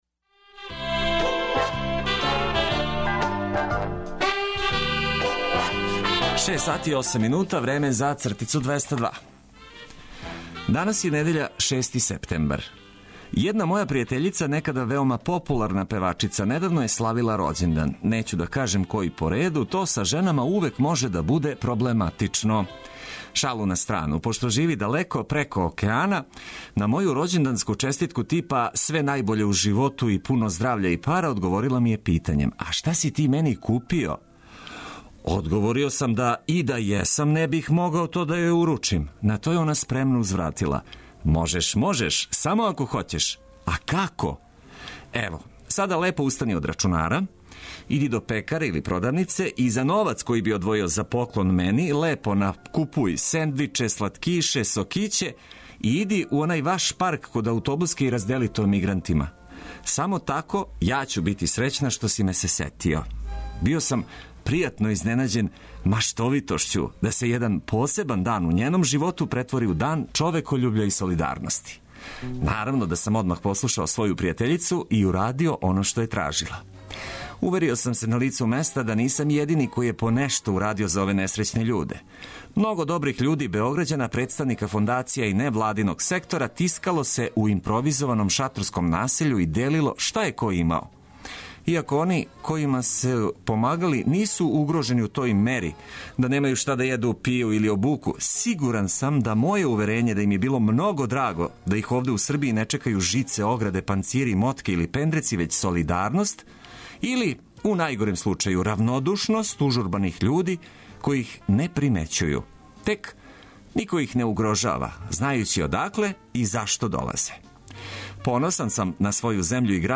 Нека недеља почне уз много музике и ведре теме.